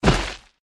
target_impact_only.ogg